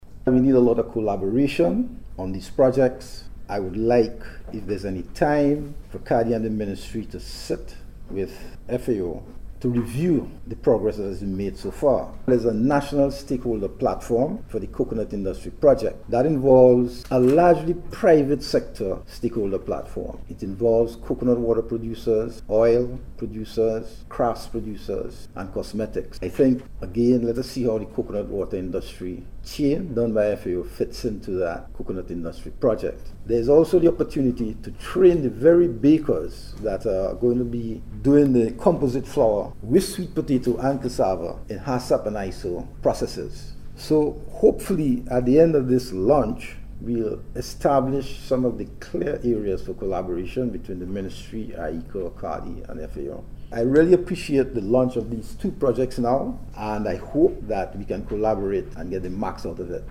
remarks at the official launch at the NIS Training Room on Monday.